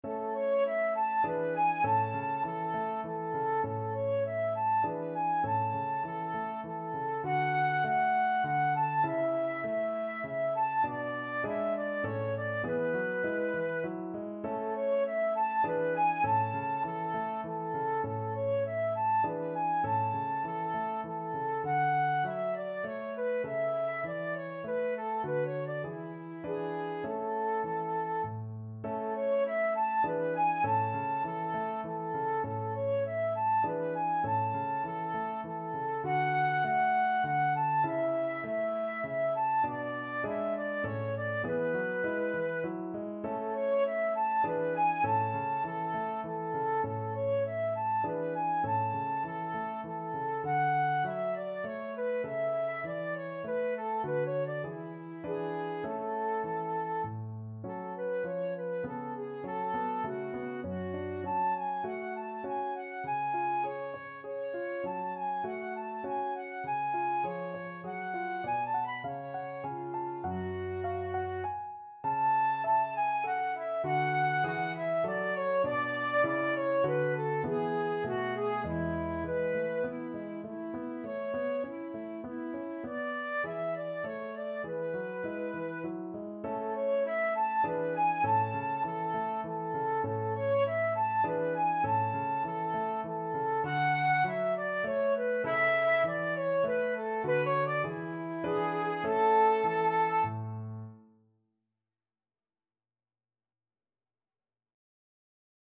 Flute
Moderato
3/4 (View more 3/4 Music)
A major (Sounding Pitch) (View more A major Music for Flute )
Classical (View more Classical Flute Music)